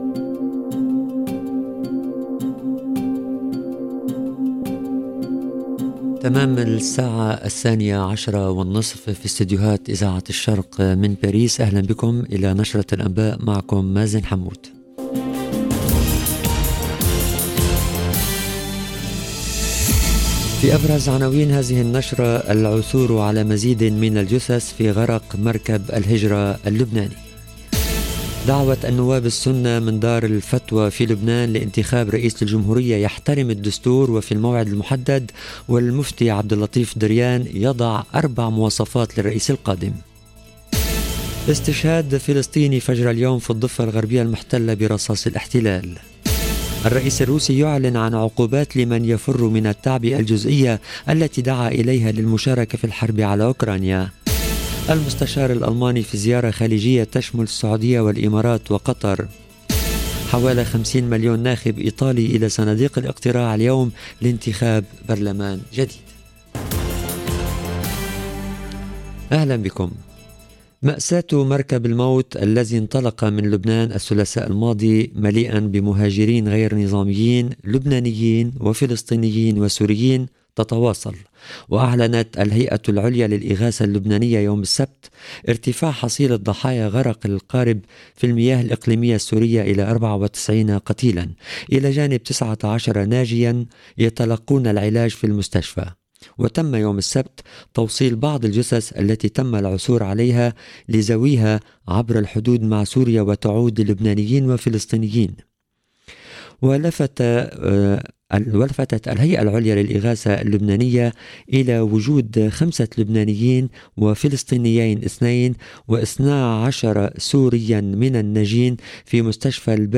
LE JOURNAL DE 12H30 EN LANGUE ARABE DU 25/9/2022